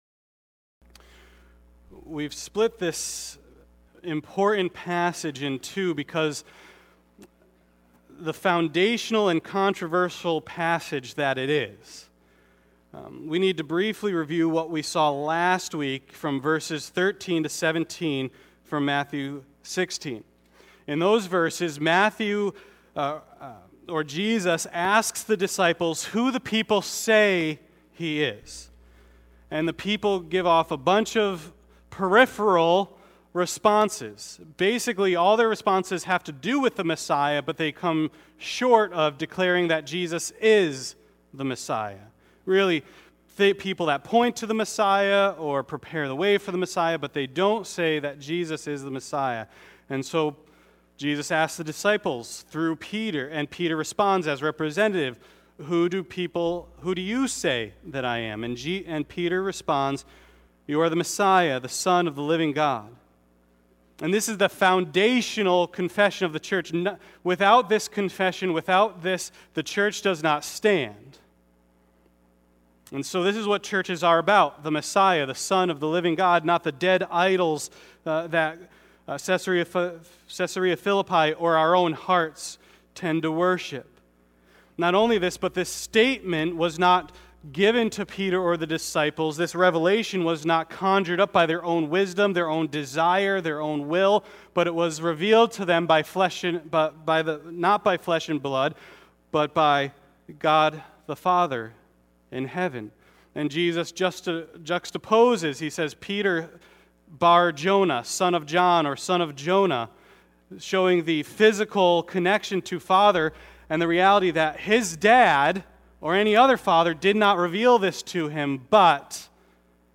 Passage: Matthew 16:13-20 Service Type: Sunday Worship